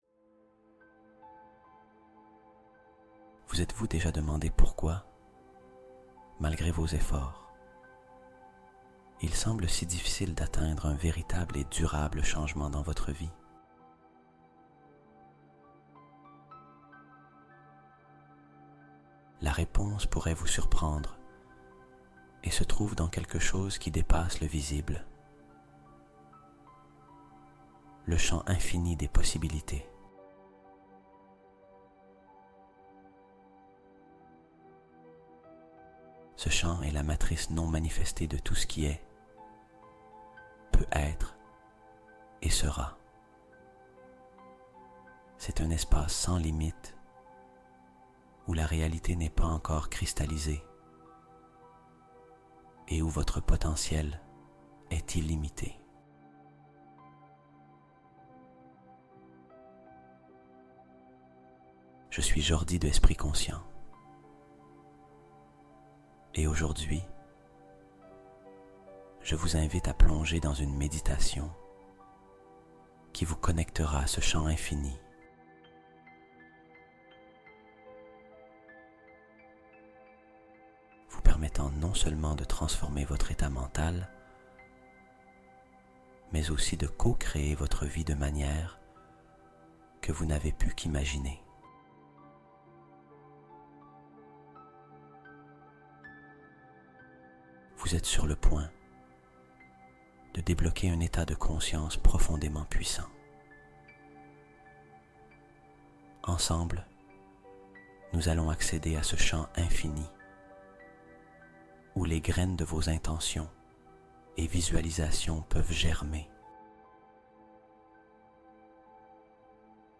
Méditation Guidée